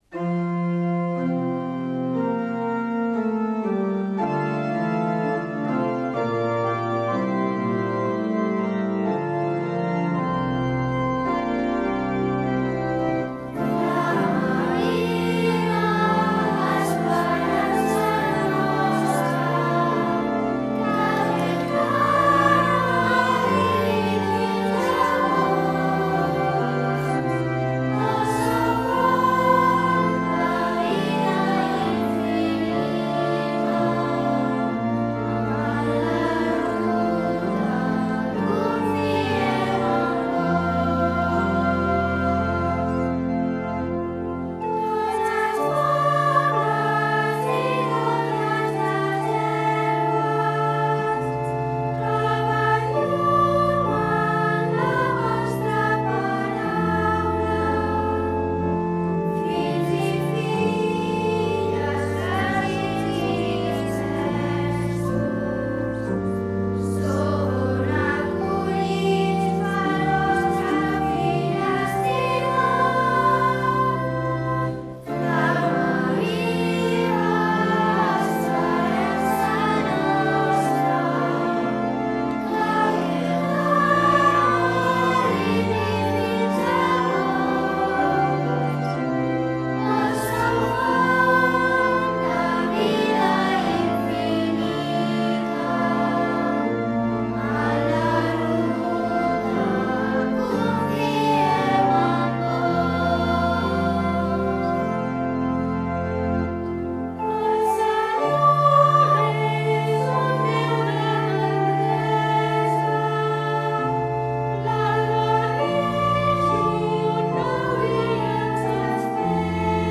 orgue
flauta